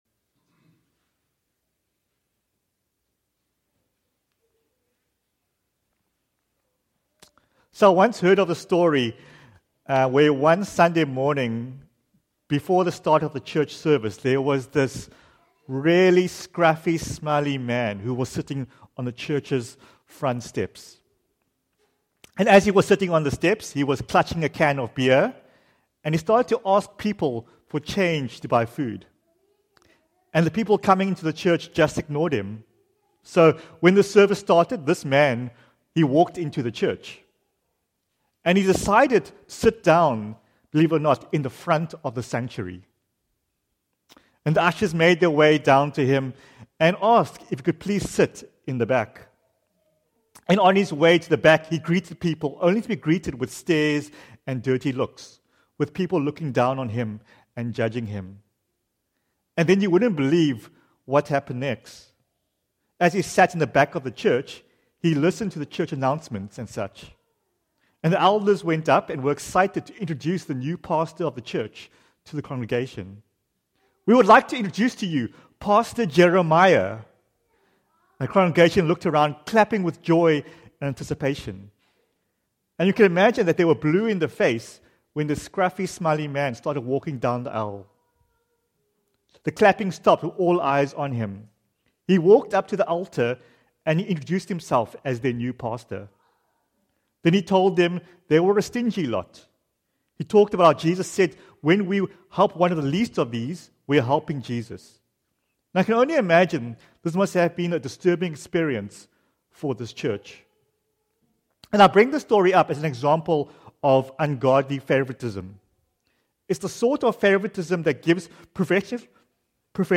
Sunday morning sermons from Jacqueline Street Alliance Church.
JSAC_February_22_Sunday_Morning.mp3